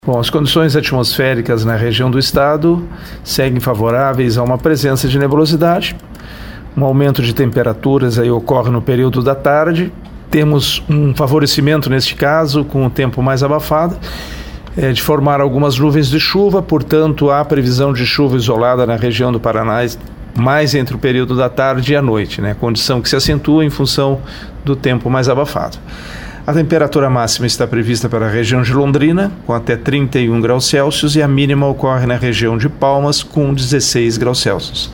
Nesta terça-feira, a tendência é para uma presença significativa de nebulosidade e possibilidade de algumas chuvas isoladas distribuídas por todo o Paraná. O meteorologista do Simepar